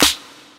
Snare (VCR Wheels).wav